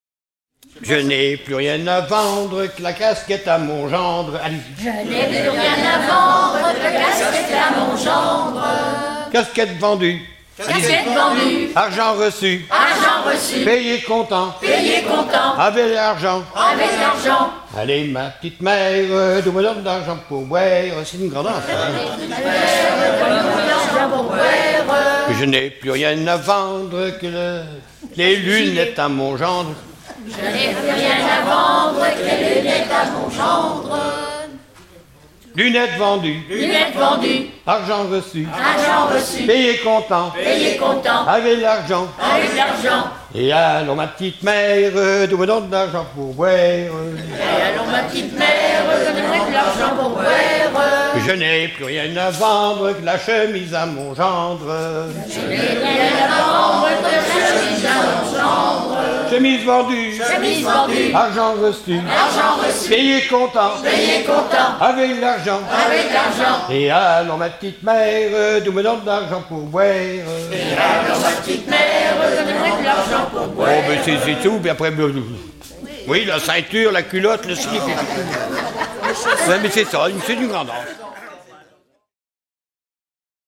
Mémoires et Patrimoines vivants - RaddO est une base de données d'archives iconographiques et sonores.
Saint-Paul-Mont-Penit
danse : ronde : grand'danse
Genre énumérative
Pièce musicale éditée